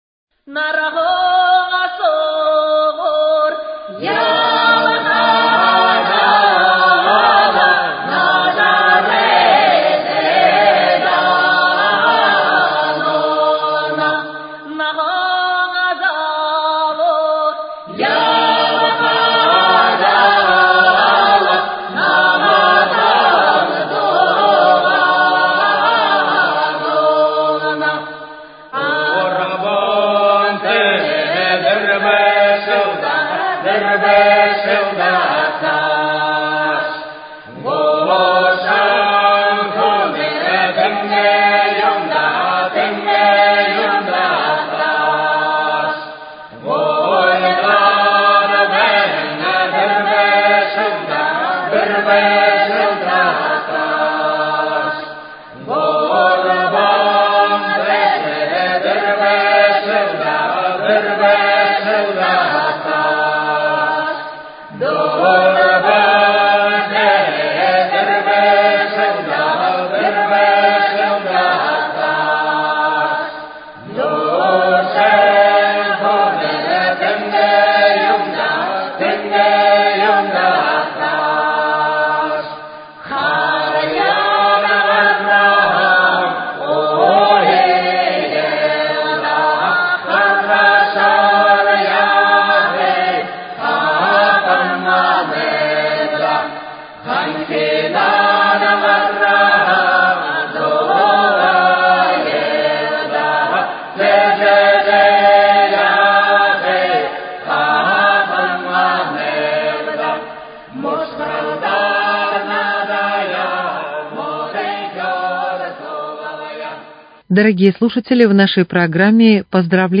Поздравление мэра Иркутска Руслана Болотова с праздником Белого месяца – Сагаалгана